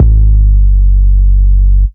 Index of /90_sSampleCDs/Club_Techno/Percussion/Kick
Kick_19.wav